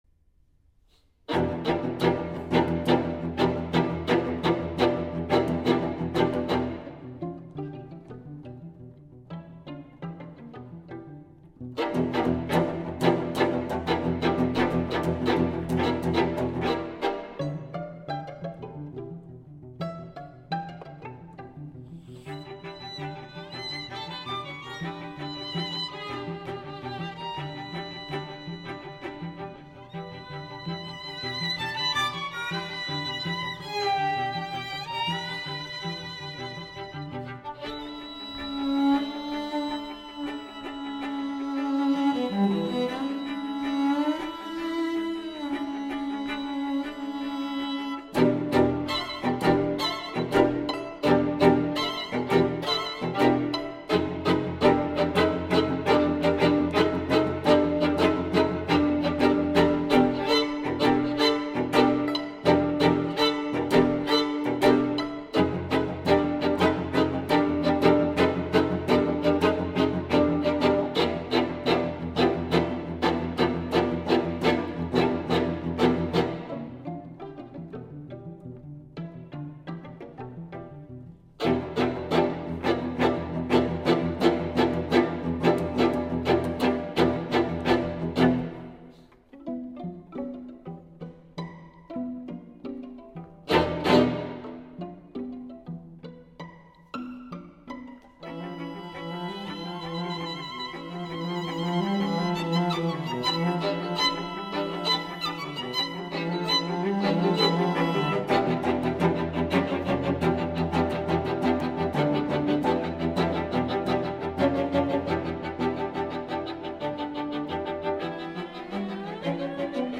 String_Quartet_No._I.mp3